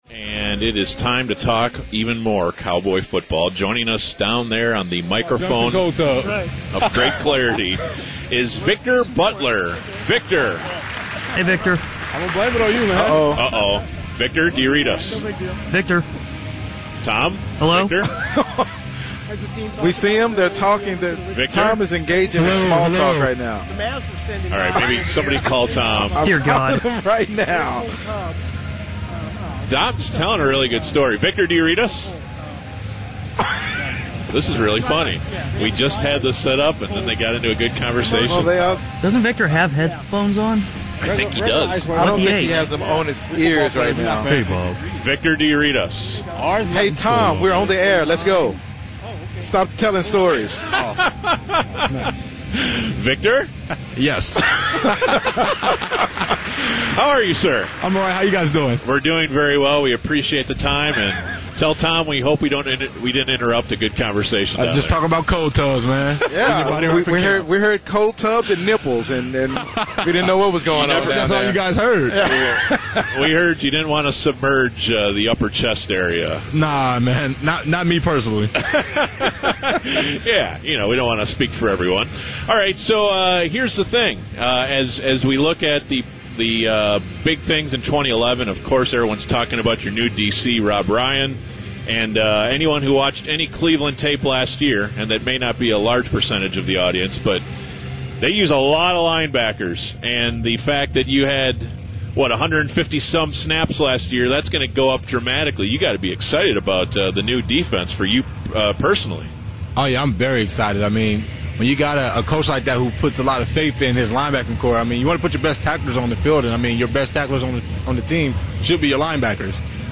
BaD Radio Interviews V-Butt (Victor Butler)